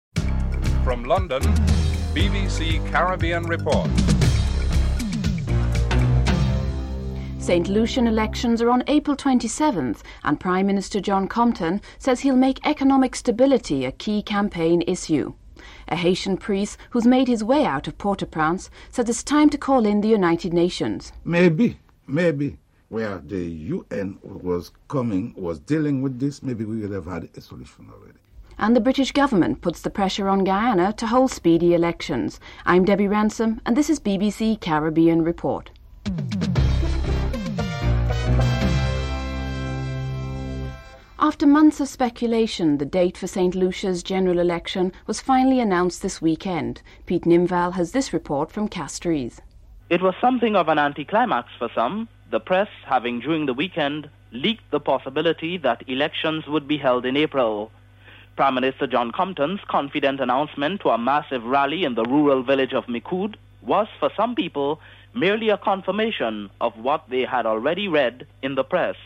The British Broadcasting Corporation
1. Headlines (00:00-00:39)
3. In an interview with the anchor, Prime Minister John Compton talks of his plan to focus on political and economic stability in his election campaign while dealing with issues such as OECS-Federation in the party’s manifesto (02:44-05:34)